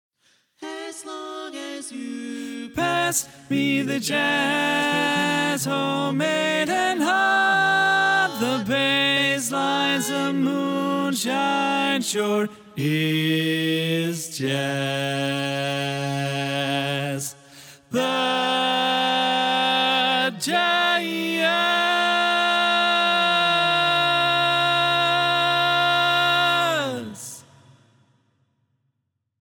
Key written in: D♭ Major
Type: Barbershop